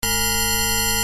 Wówczas drugi dźwięk pomimo tego, że jest tak jakby szybciej odtwarzany (2n zamiast n) posiada niższe brzmienie: